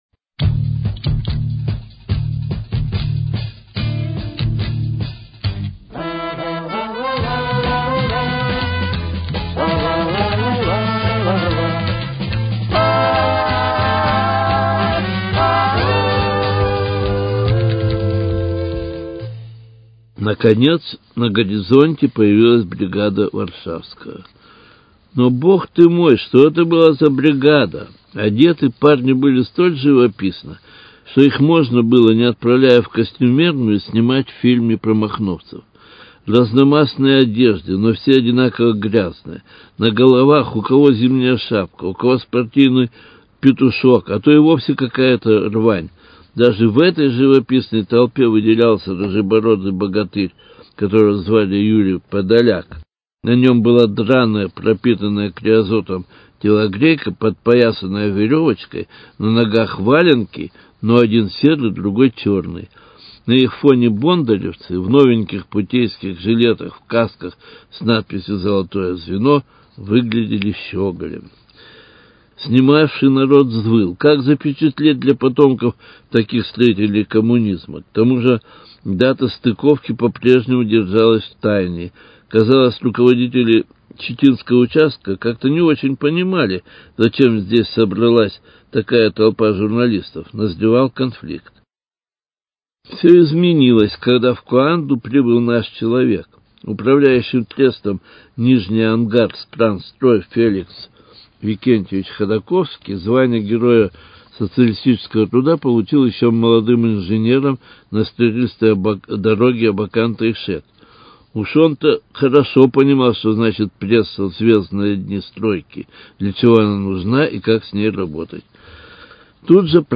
Цикл радио-очерков